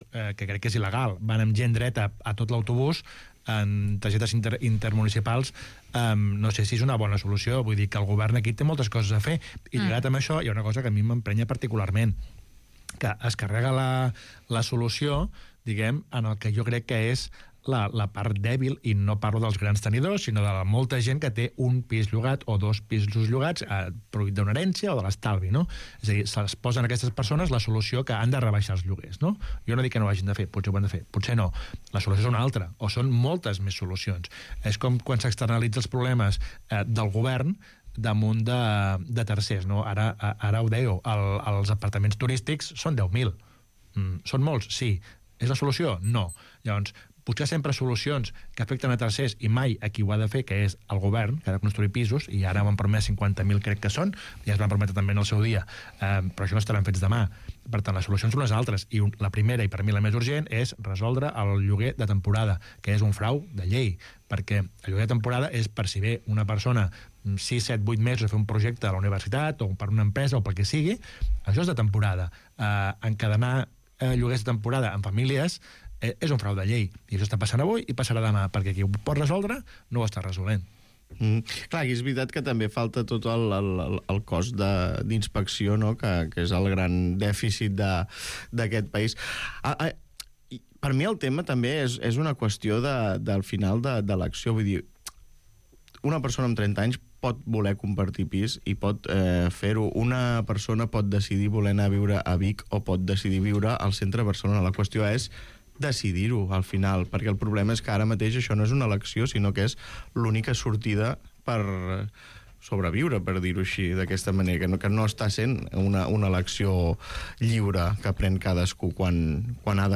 El Matí a Ràdio Estel. Ràdio Estel Enllaços relacionats Enllaç a l'entrevista